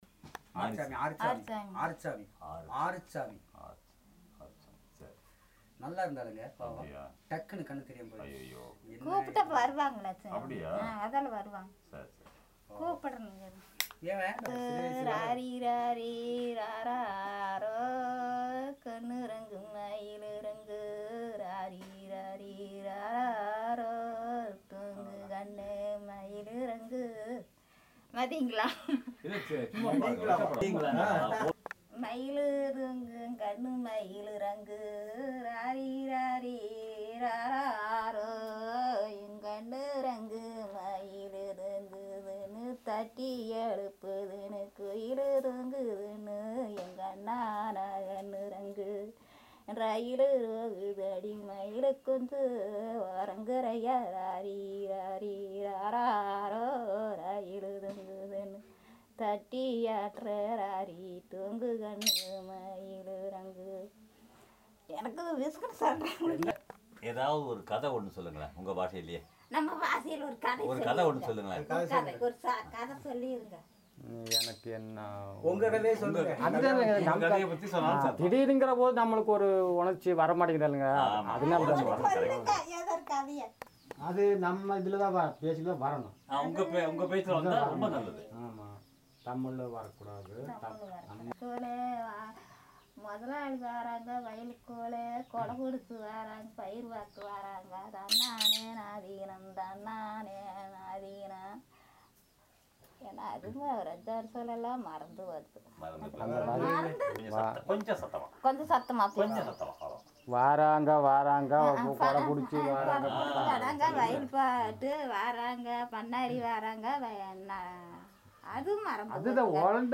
Performance of folk songs and elicitation of general sentences